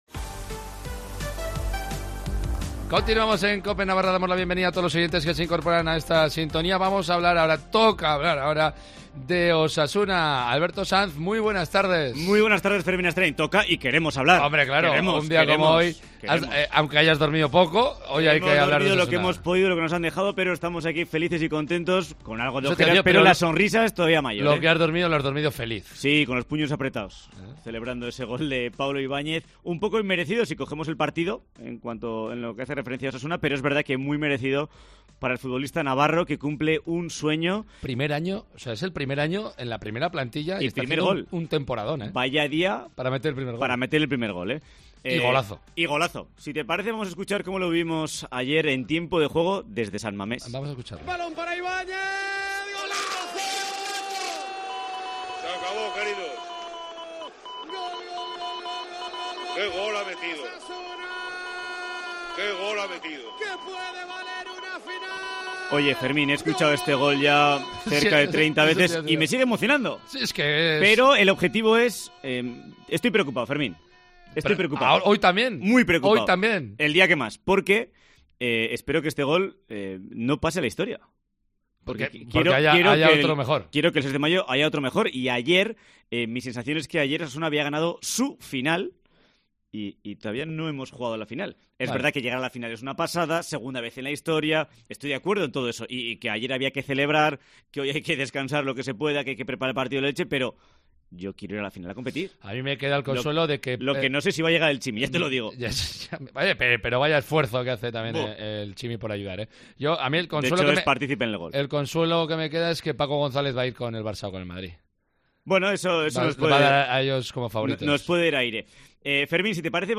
Sonidos del pase a la final de Copa